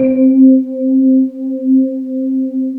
Index of /90_sSampleCDs/USB Soundscan vol.28 - Choir Acoustic & Synth [AKAI] 1CD/Partition D/08-SWEEPOR